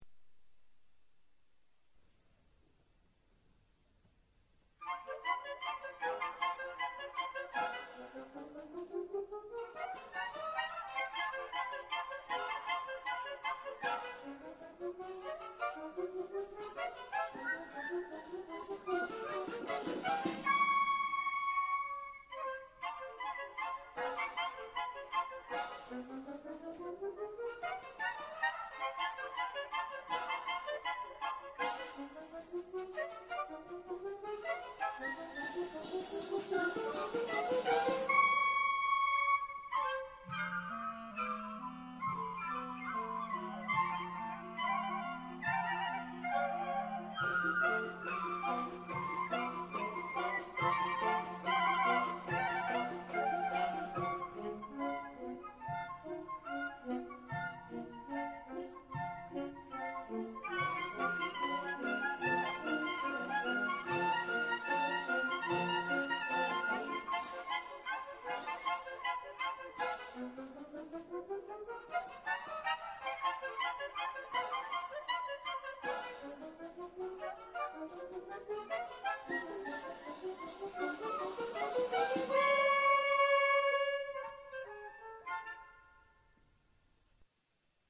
地點: 音乾乾的中山堂